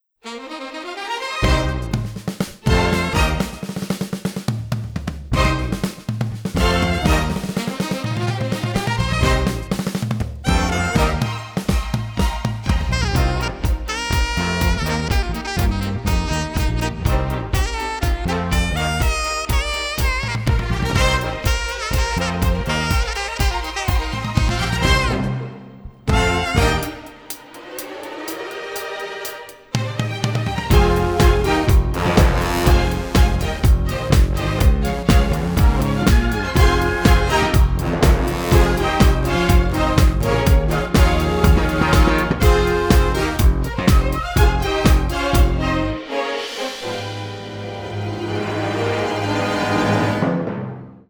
dynamic and classic fully orchestral adventure score